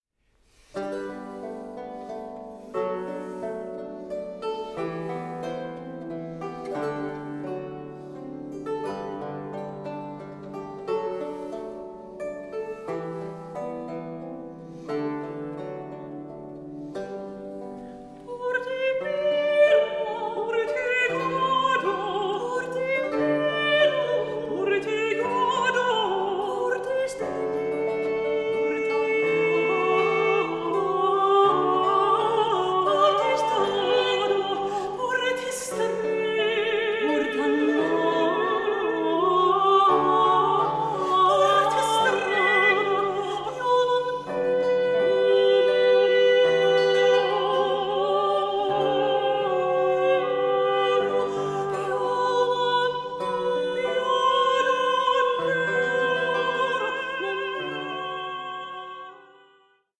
and her last soprano recording